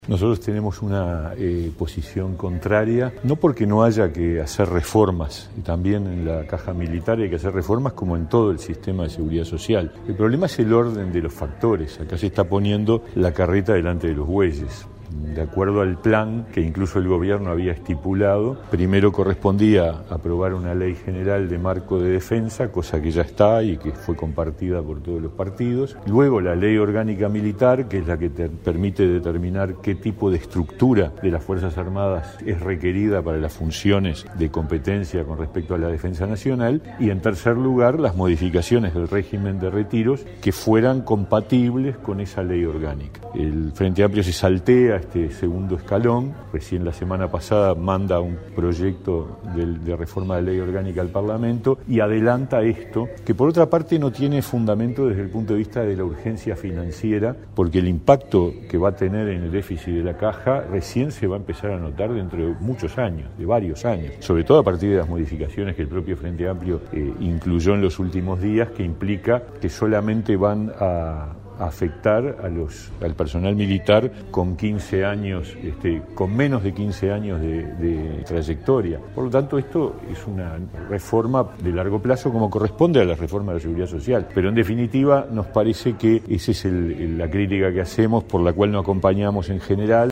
Escuche a Mieres aquí: